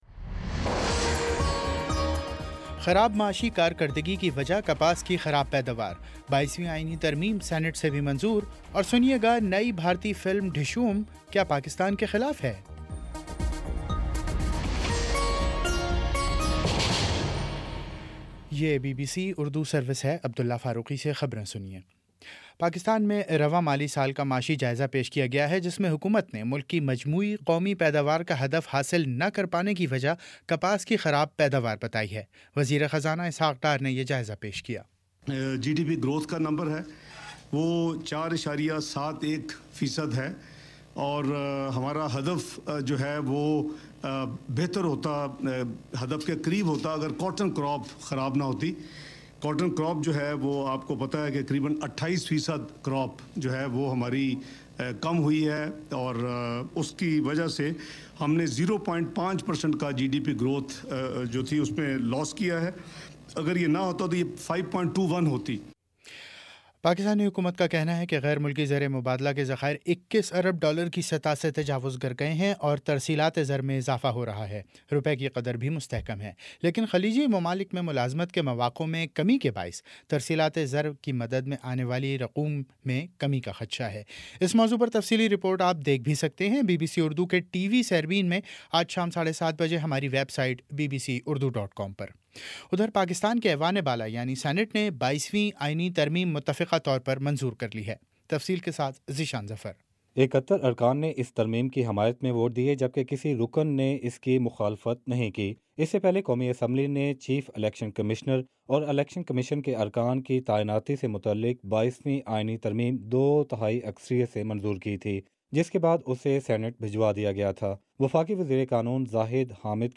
جون 02: شام چھ بجے کا نیوز بُلیٹن